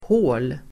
Uttal: [hå:l]